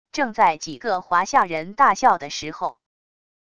正在几个华夏人大笑的时候wav音频